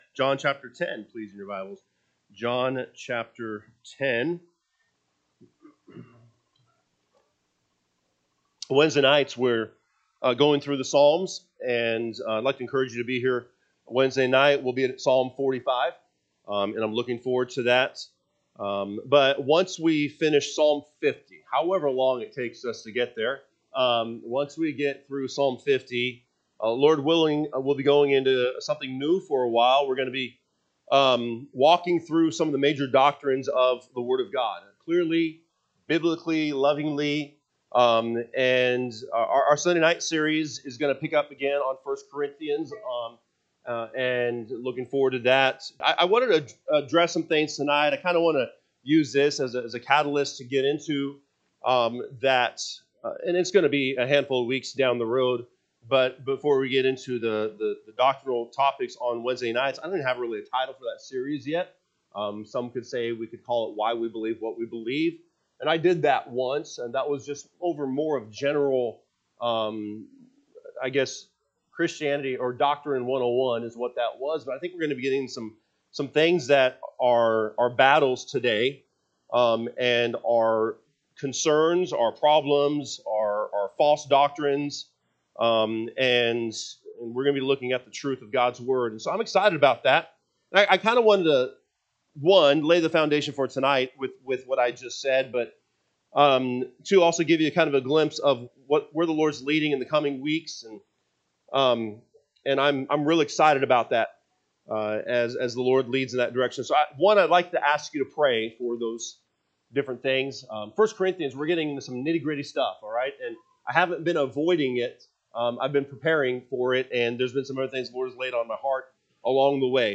February 15, 2026 pm Service John 10:22-30 (KJB) 22 And it was at Jerusalem the feast of the dedication, and it was winter. 23 And Jesus walked in the temple in Solomon’s porch. 24&…
Sunday PM Message